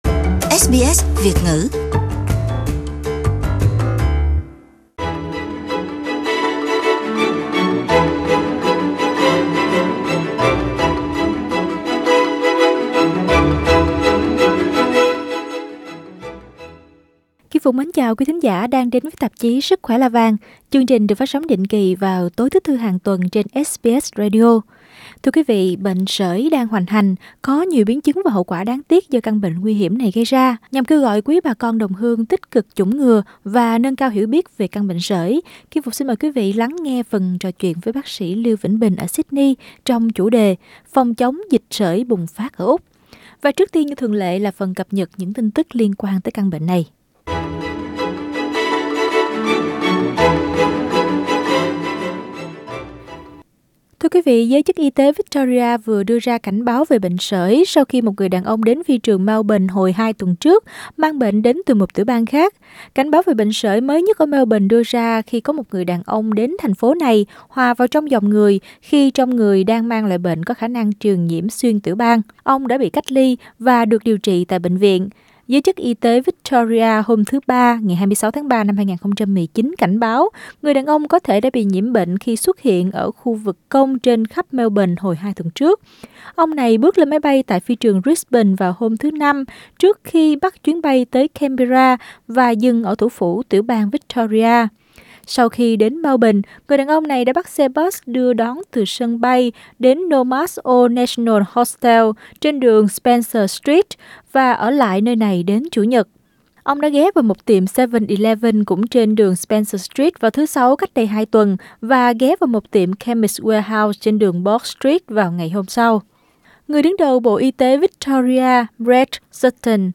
Nhưng trước tiên như thường lệ là phần cập nhật những tin tức liên quan đến căn bệnh này.